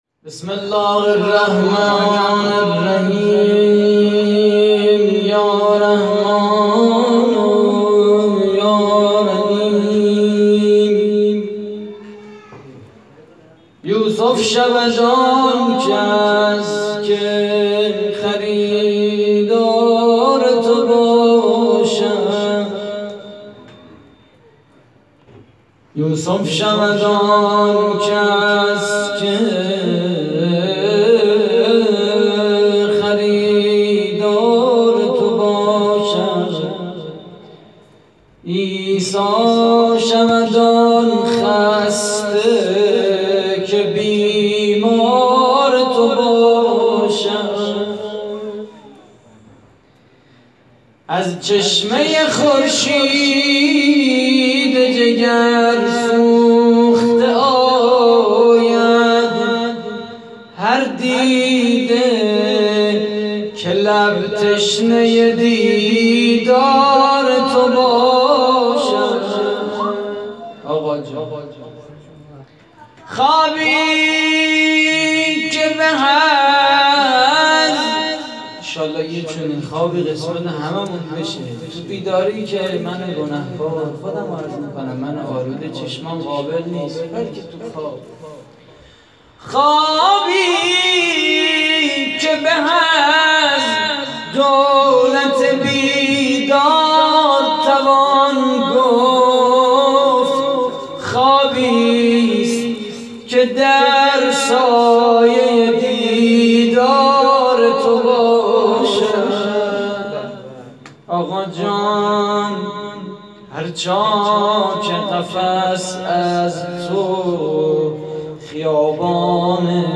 جشن ولادت امام زمان (عج)
صوت مراسم:
مدح: یوسف شود آنکس که خریدار تو باشد؛ پخش آنلاین |